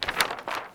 Paper.wav